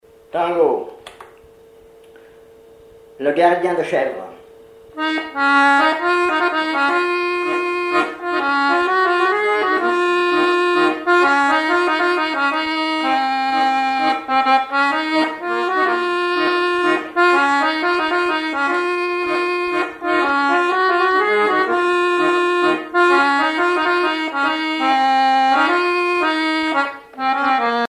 accordéon(s), accordéoniste
danse : tango
Genre strophique
Catégorie Pièce musicale inédite